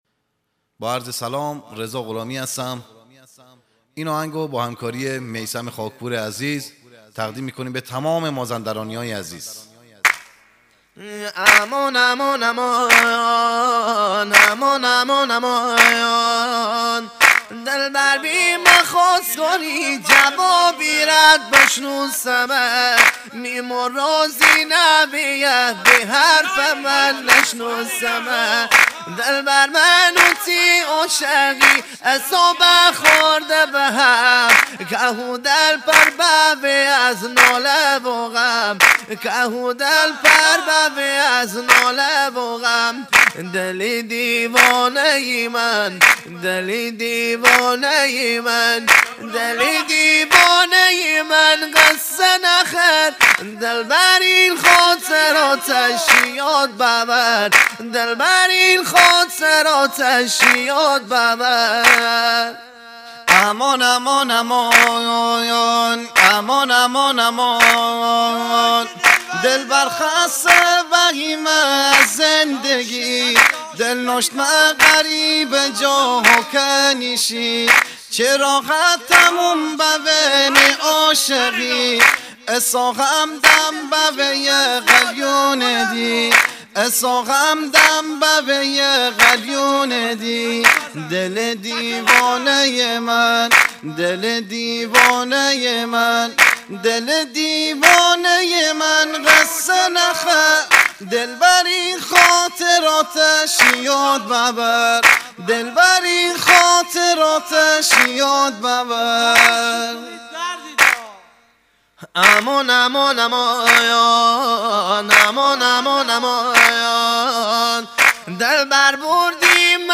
آهنگ جدید مازندرانی
آهنگ شاد